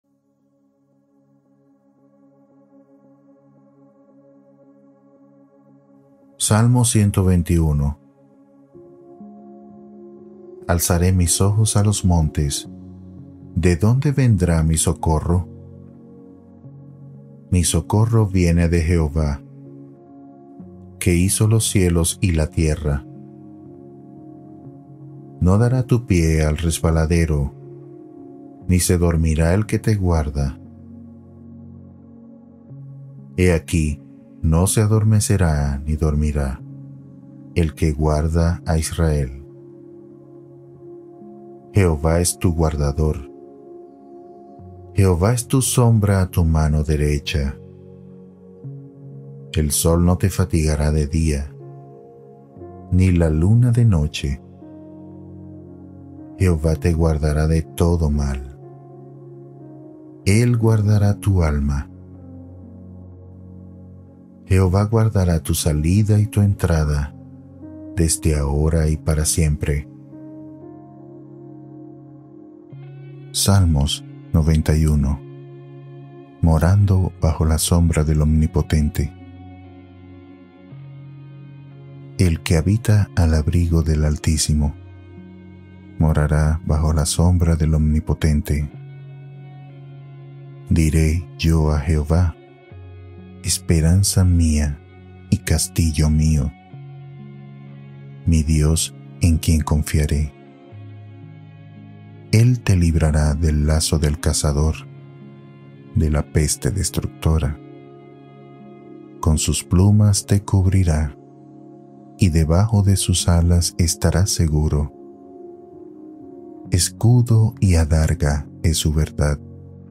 Duerme profundamente con la Palabra de Dios | Biblia hablada 1960